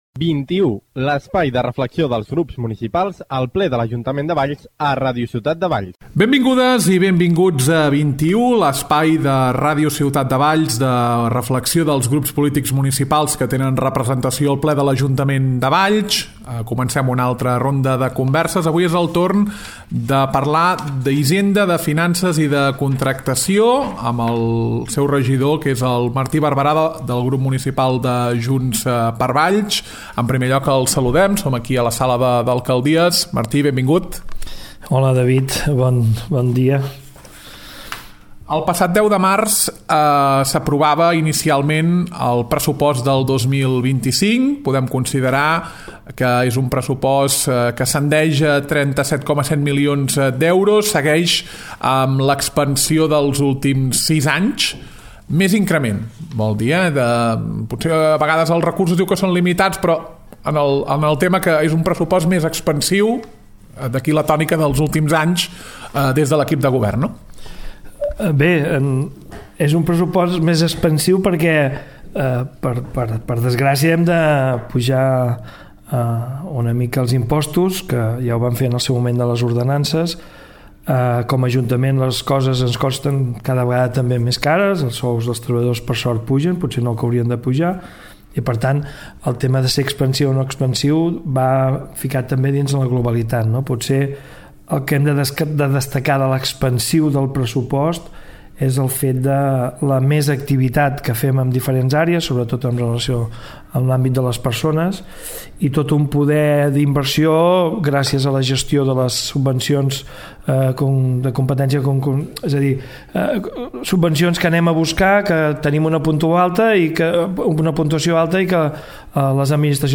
Avui és el torn de Junts per Valls. Entrevista a Martí Barberà, regidor d’Hisenda, Finances i Contractacions.